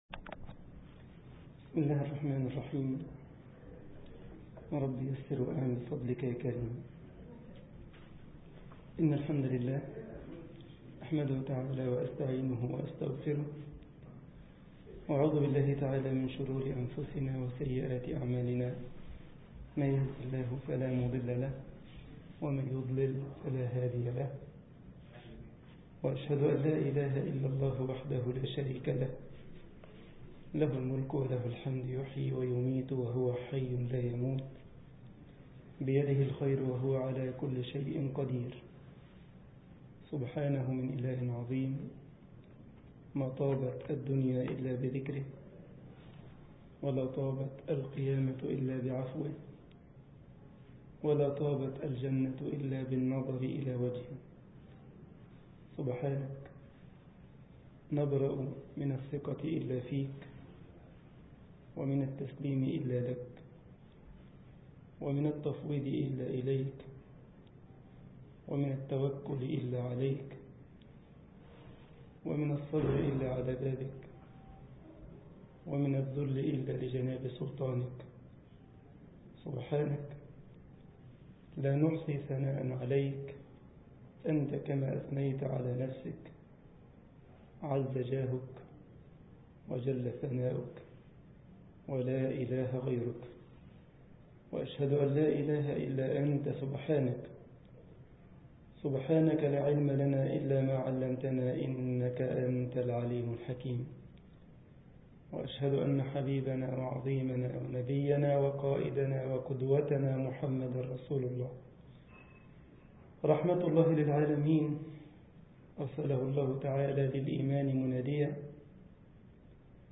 مسجد الجمعية الإسلامية بكايزرسلاوترن ـ ألمانيا درس